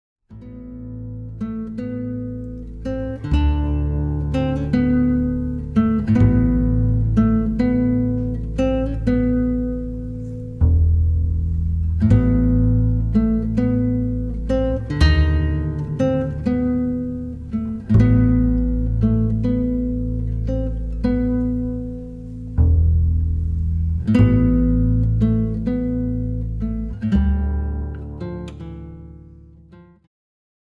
ジャンル Jazz-Rock系
Progressive
アコースティック
アバンギャルド
インストゥルメンタル